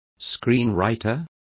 Complete with pronunciation of the translation of screenwriters.